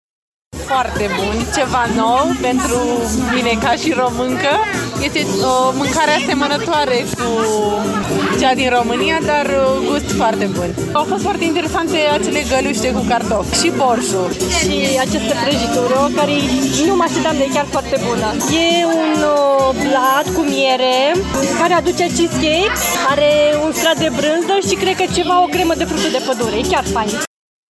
VOXURI.mp3